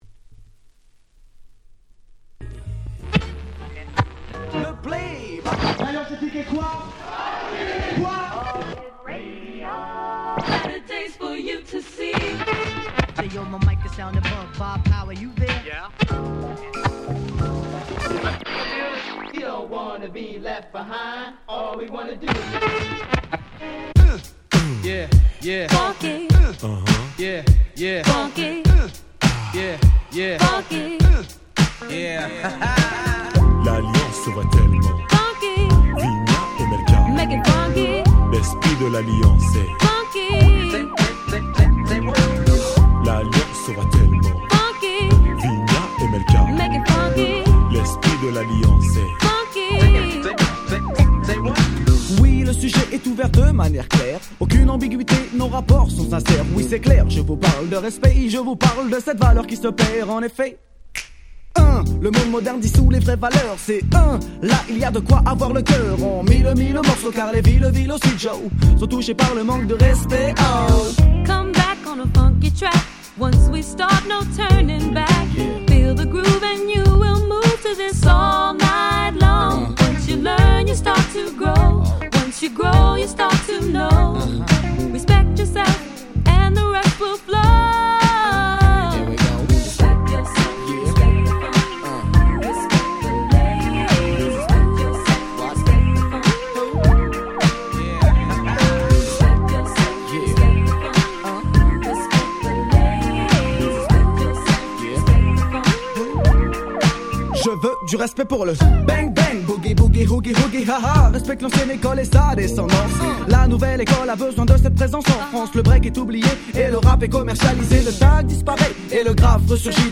コーナーストーン 90's キャッチー系 NJS ハネ系 New Jack Swing ニュージャックスウィング